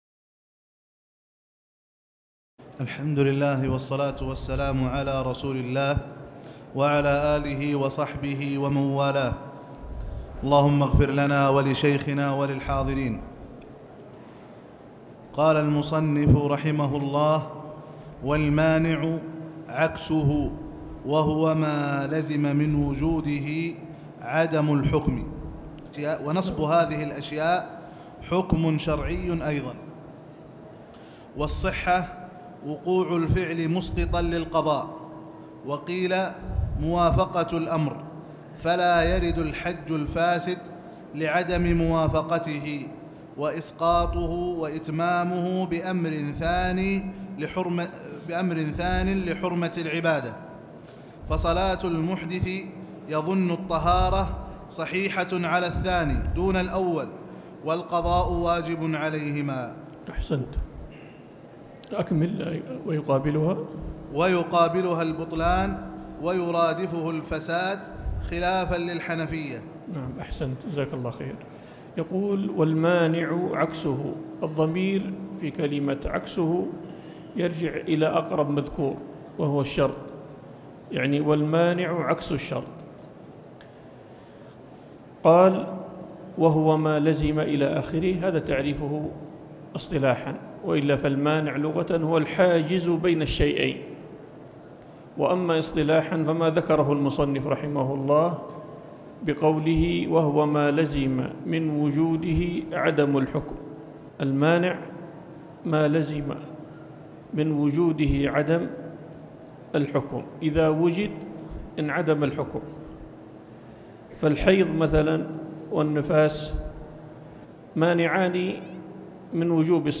تاريخ النشر ٧ ذو القعدة ١٤٤٠ المكان: المسجد الحرام الشيخ